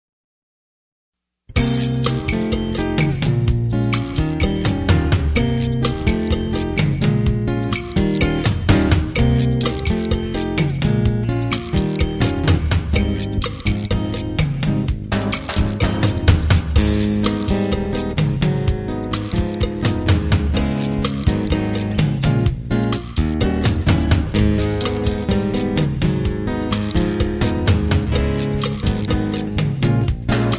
Instrumental Tracks.